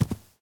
grass1.ogg